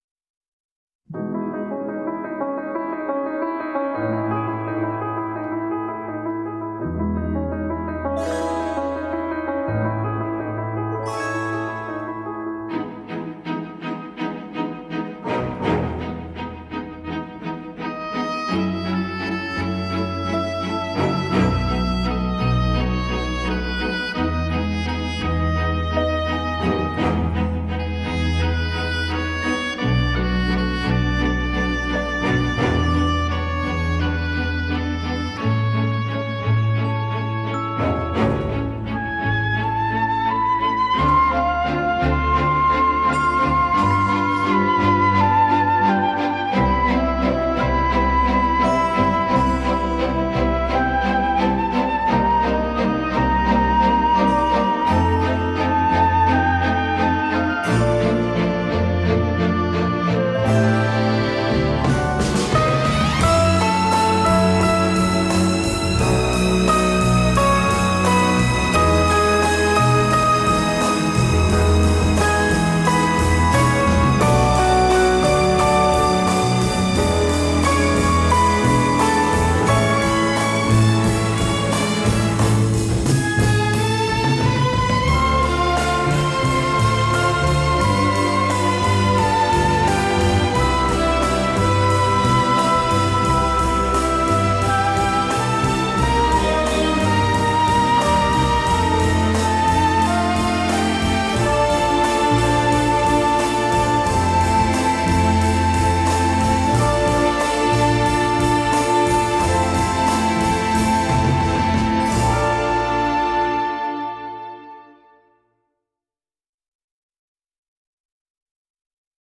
radiomarelamaddalena / STRUMENTALE / ORCHESTRE /
Original Motion Picture Soundtrack